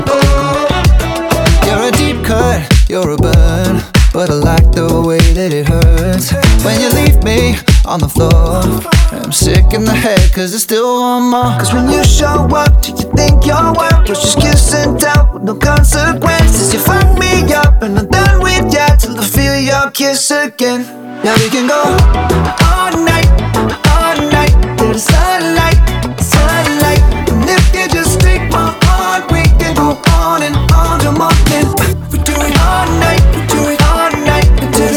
2025-06-22 Жанр: Поп музыка Длительность